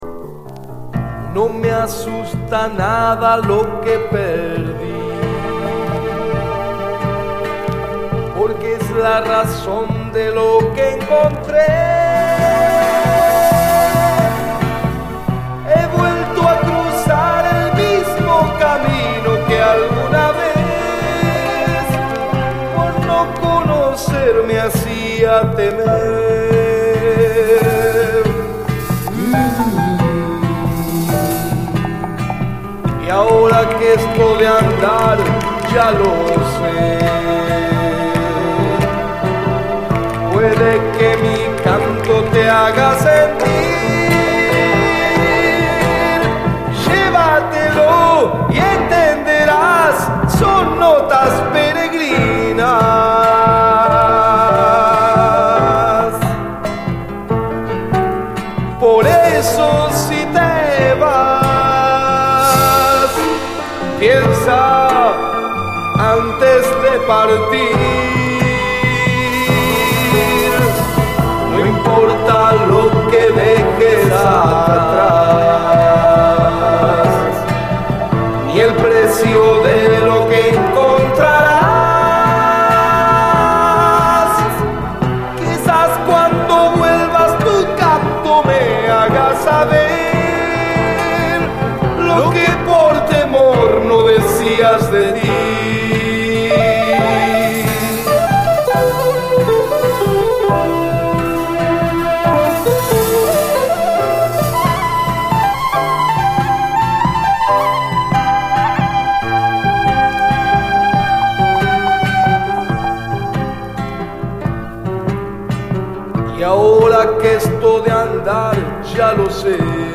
JAZZ FUNK / SOUL JAZZ, JAZZ, LATIN
エレピやシンセの透明感ある神秘的な響きに酔う一枚！